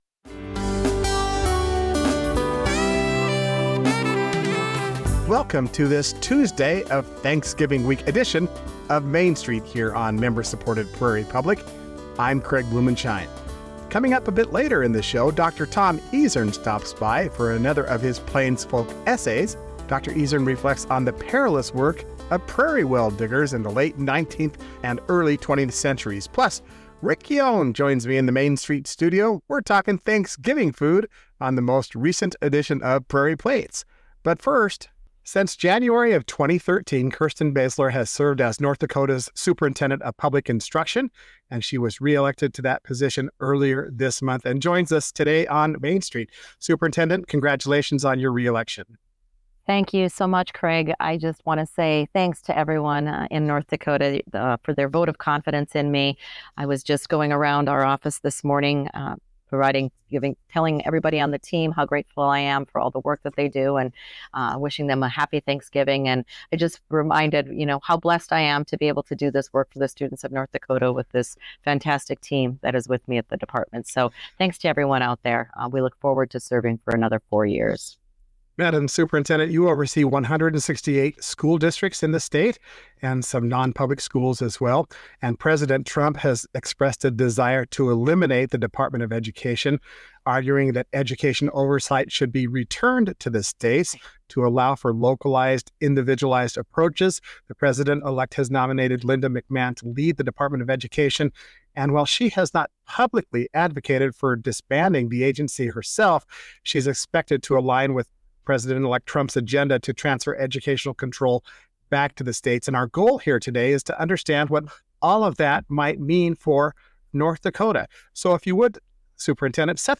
Listen for interviews with authors, artists, and newsmakers that tell the story of our region.